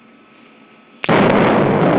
crash.au